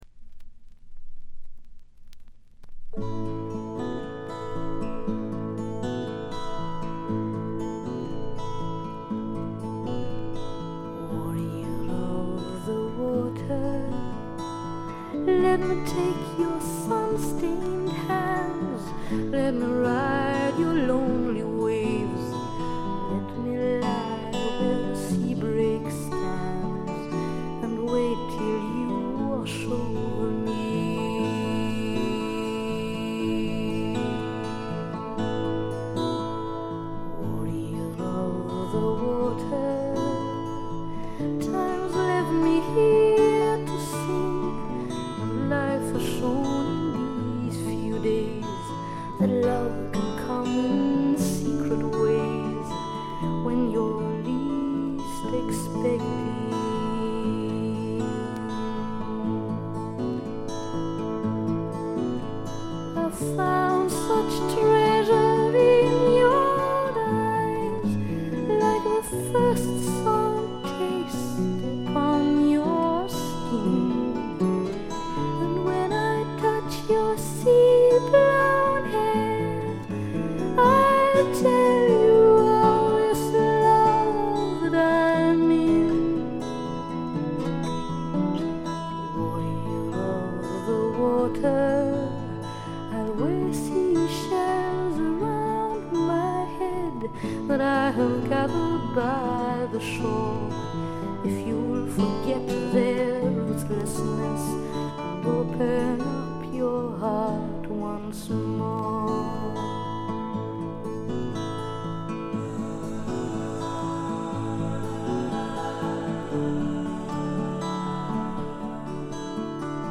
静音部でバックグラウンドノイズ、チリプチ少々、散発的なプツ音少し。
ほとんど弾き語りのような曲が多いのもよいですね。
試聴曲は現品からの取り込み音源です。